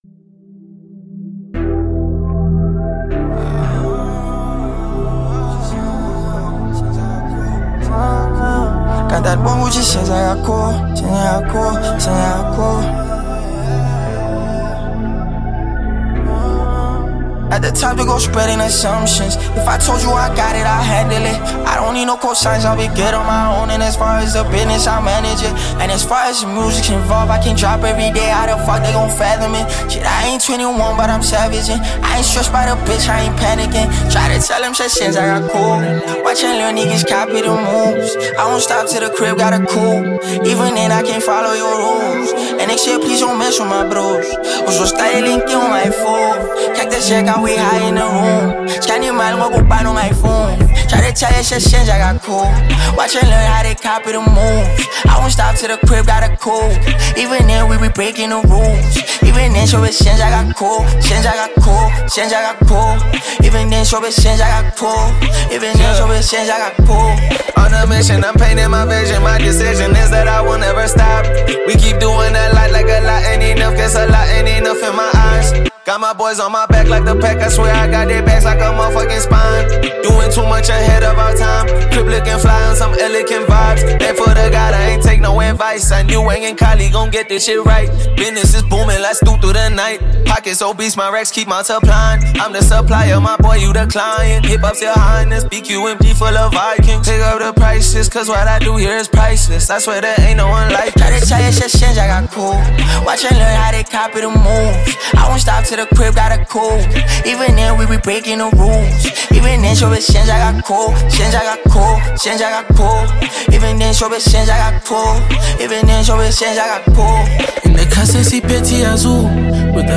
a rapper, singer, and producer from South Africa